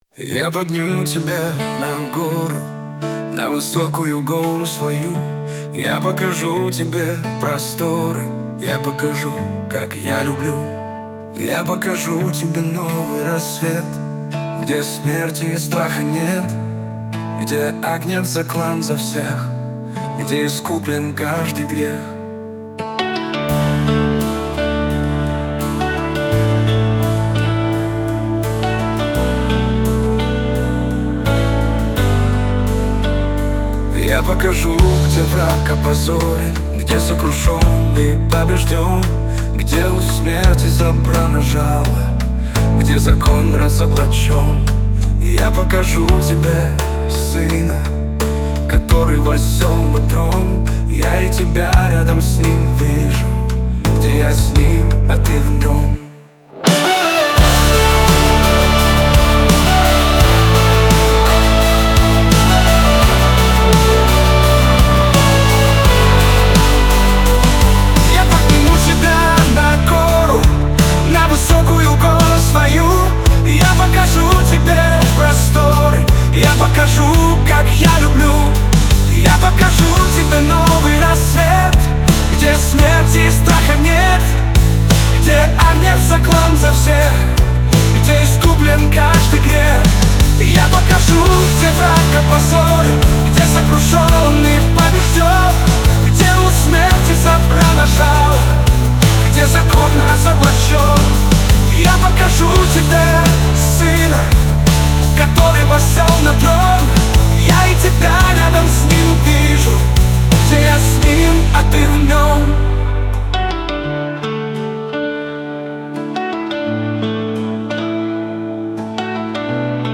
песня ai
165 просмотров 257 прослушиваний 12 скачиваний BPM: 85